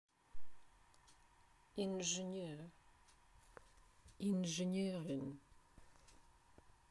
Klikom na strelicu čućete izgovor svake reči koja označava profesiju.